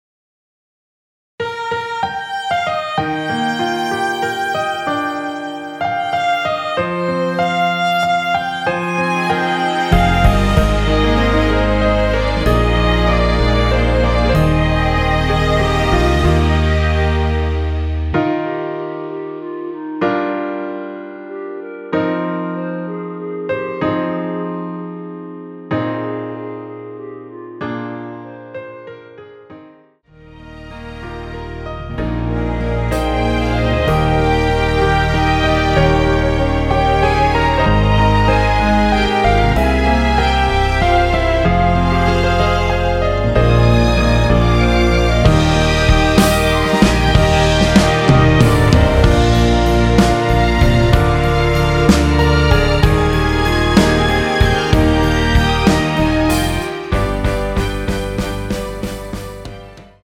원키에서(+4)올린 1절후 후렴으로 진행되는 멜로디 포함된 MR입니다.
Eb
앞부분30초, 뒷부분30초씩 편집해서 올려 드리고 있습니다.
중간에 음이 끈어지고 다시 나오는 이유는